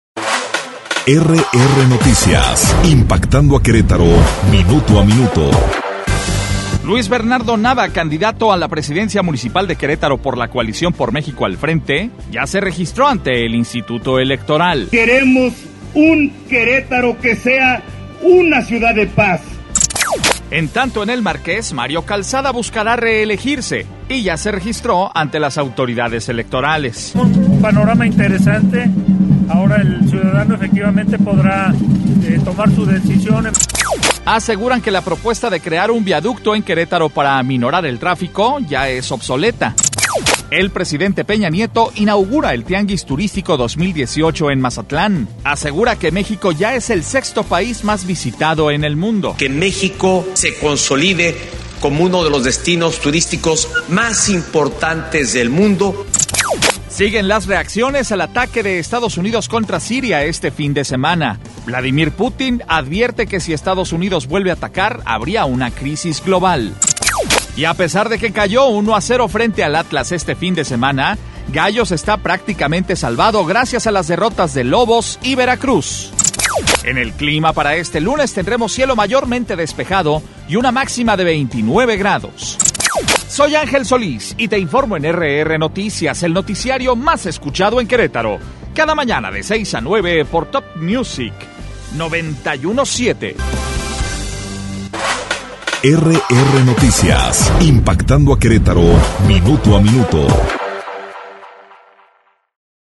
Resumen Informativo 16 de abril - RR Noticias